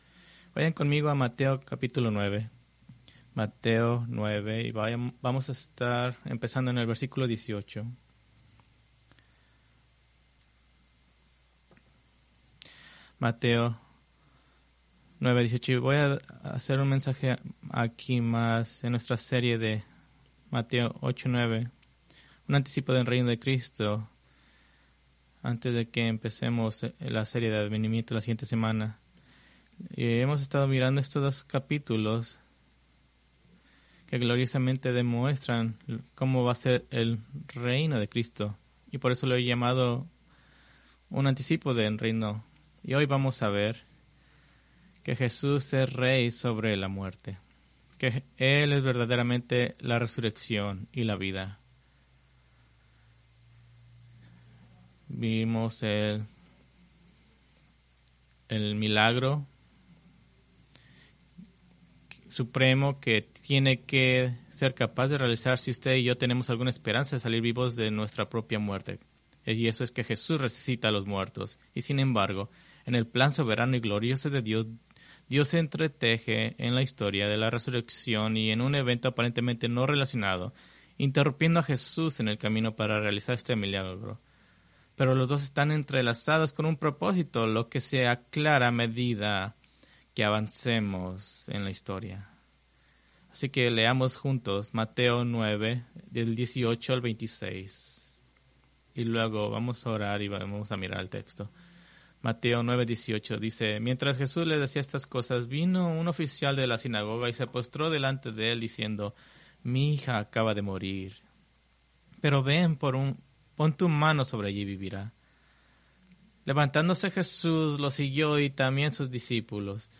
Preached December 1, 2024 from Mateo 9:18-26